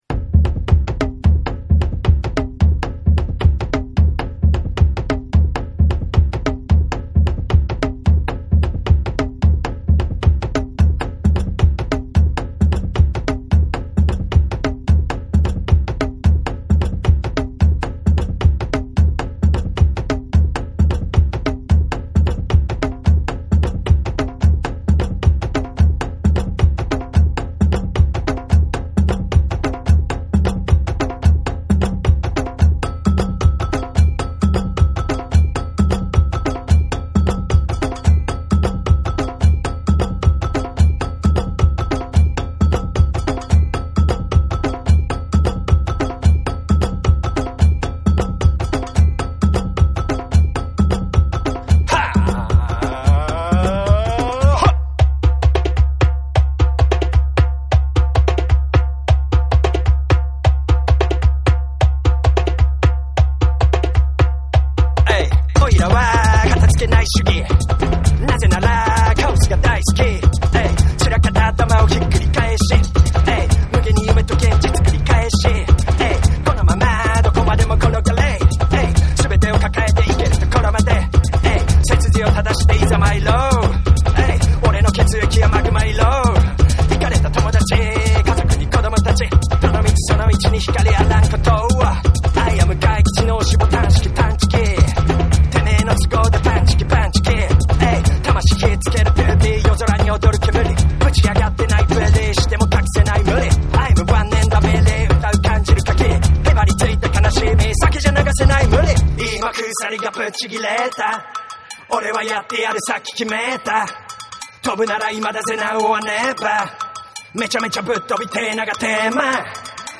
JAPANESE / BREAKBEATS / NEW RELEASE(新譜)